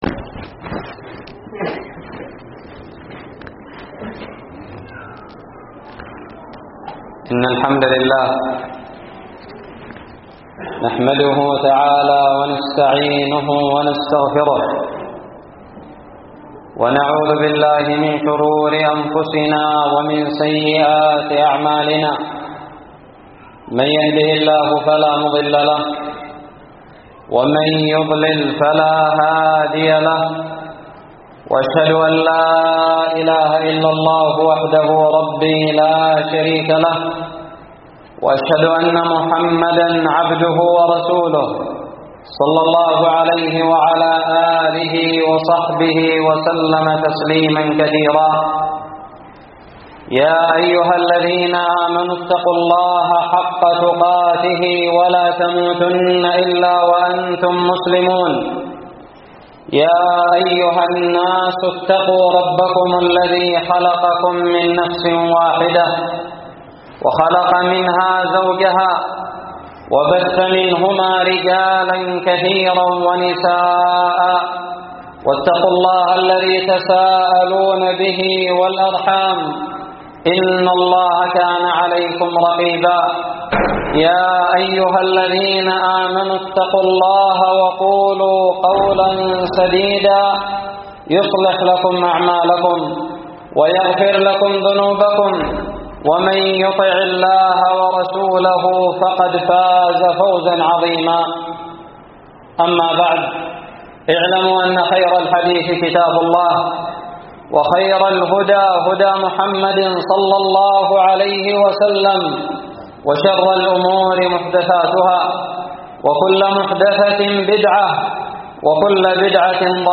خطب الجمعة
ألقيت بدار الحديث السلفية للعلوم الشرعية بالضالع في 29 رجب 1437هــ